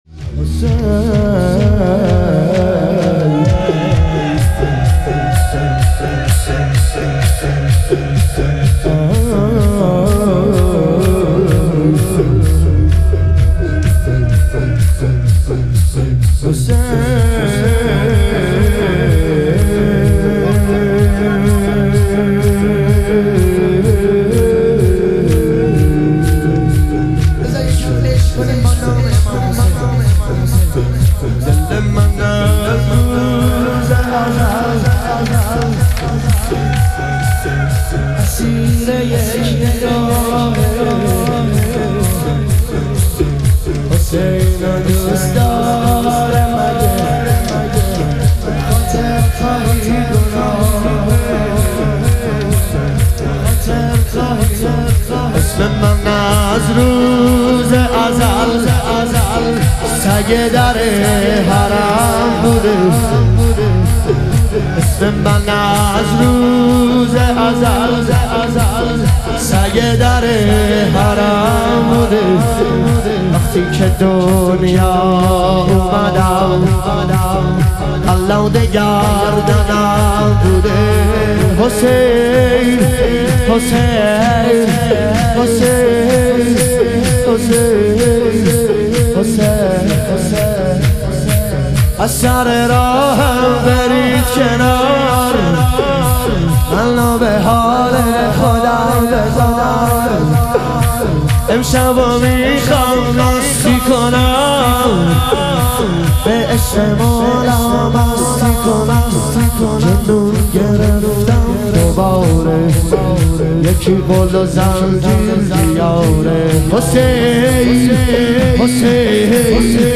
شهادت امام صادق علیه السلام - شور